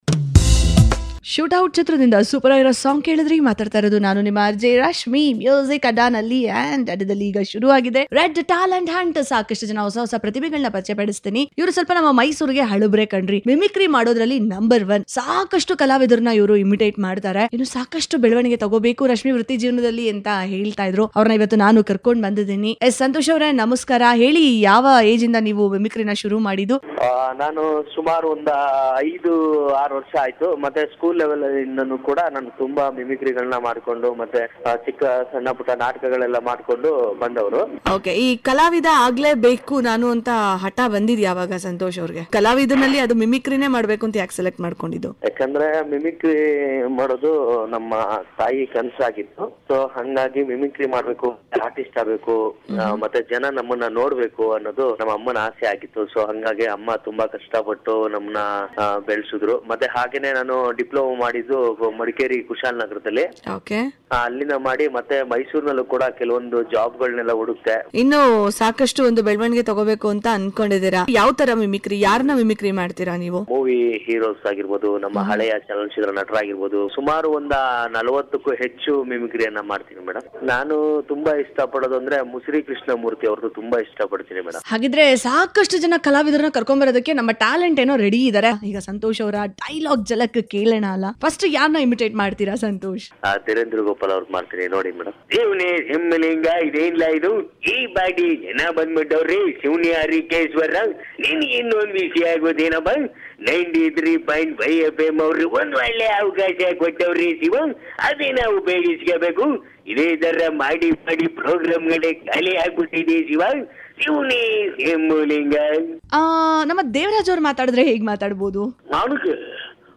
ನೀವು ಮಿಮಿಕ್ರಿ ಝಲಕ್ ಕೇಳಿ ಮಸ್ತ್ ಮಜಾ ಮಾಡಿ